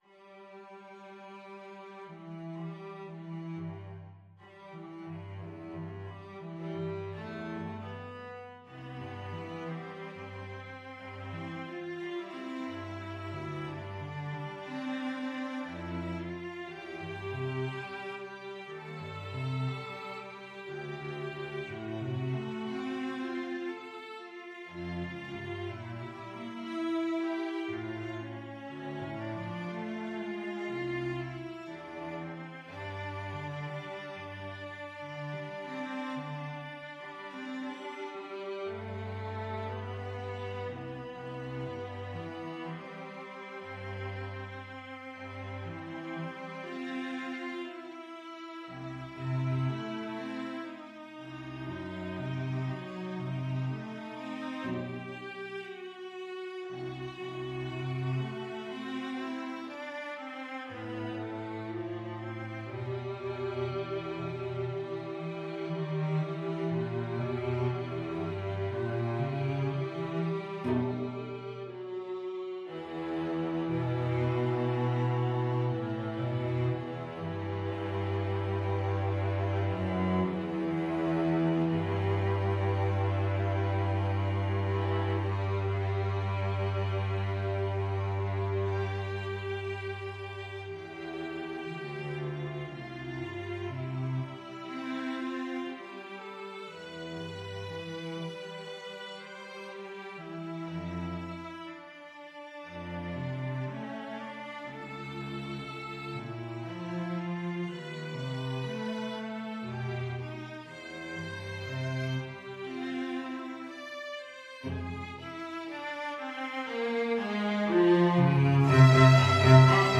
Sehr langsam